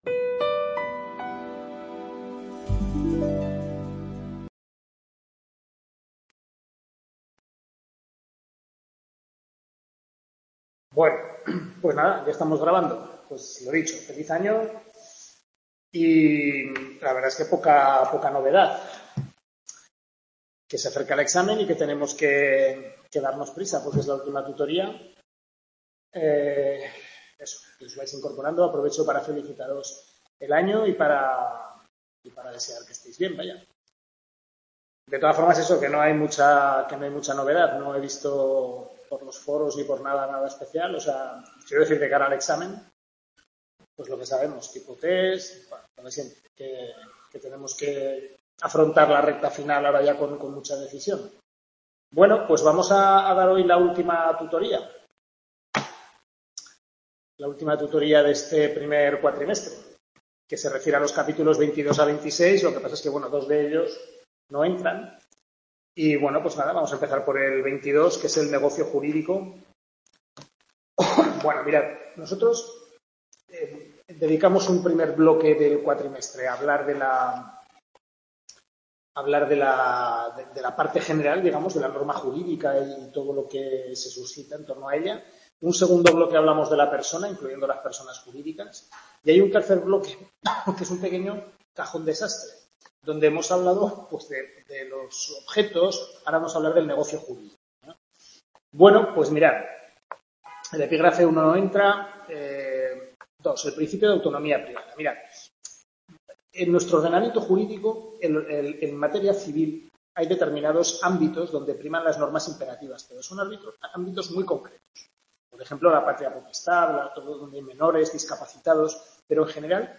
Sexta y última tutoría del primer cuatrimestre. Civil I (Parte General).